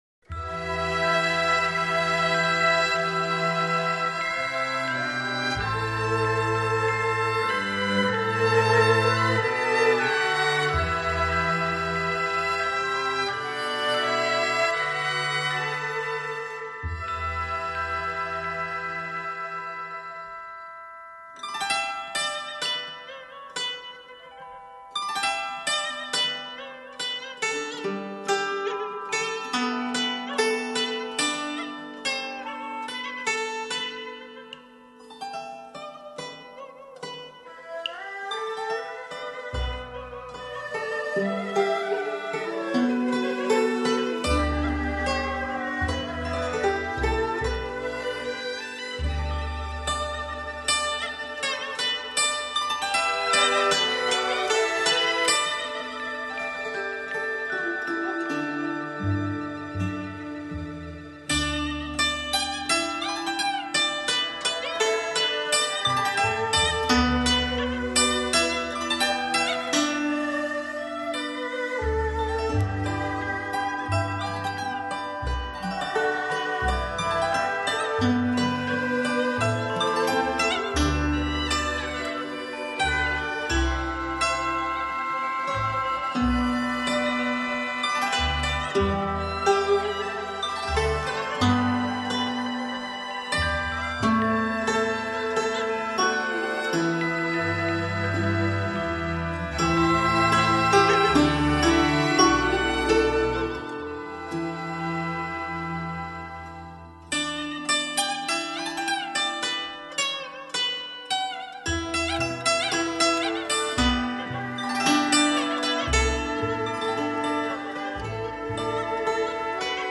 潮州传统乐曲